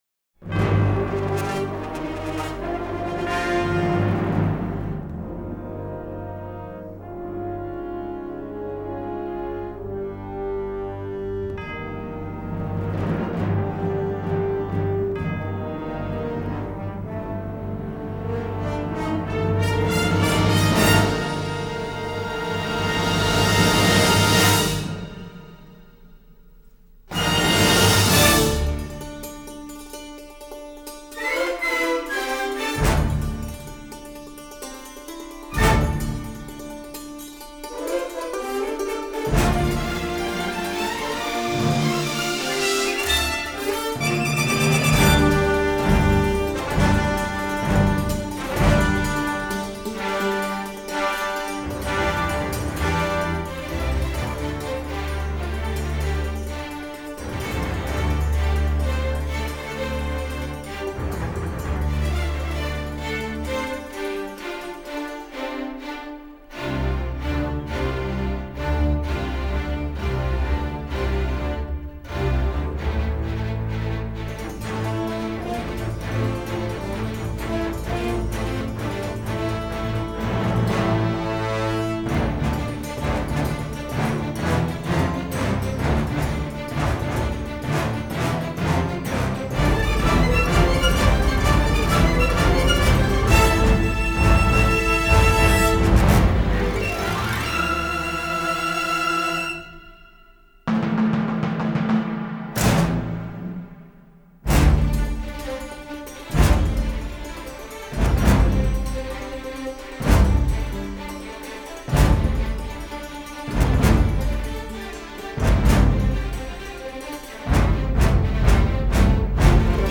Everything has been remastered from superior master elements